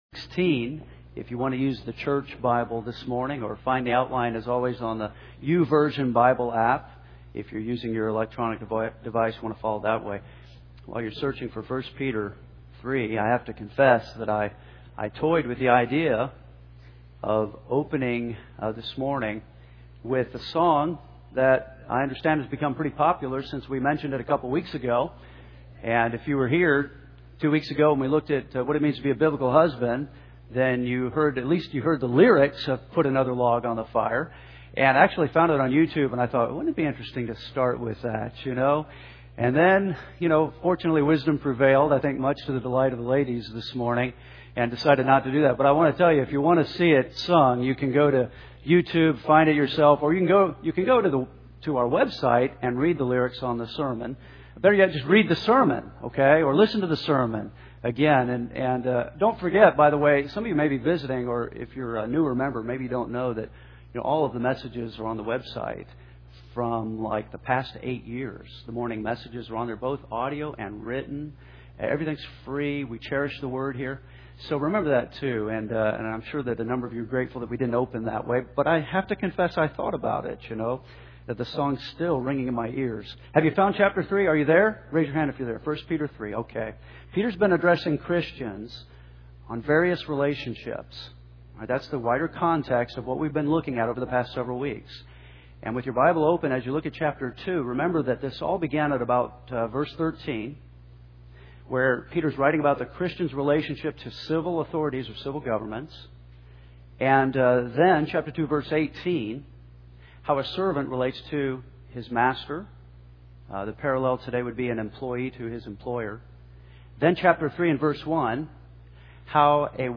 Henderson’s First Baptist Church, Henderson